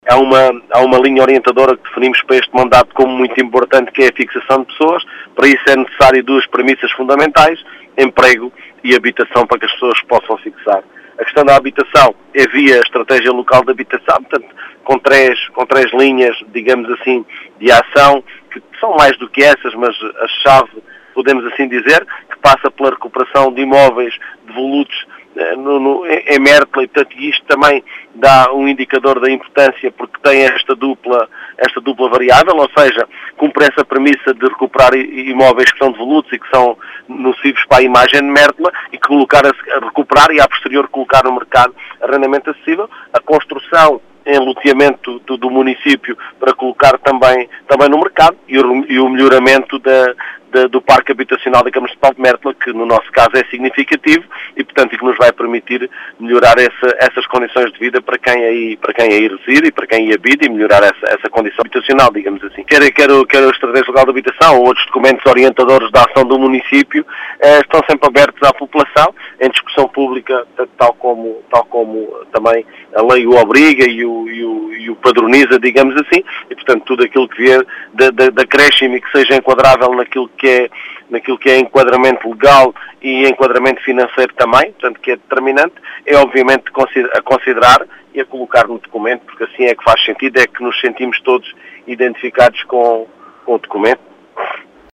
As explicações são de Mário Tomé, presidente da Câmara Municipal de Mértola.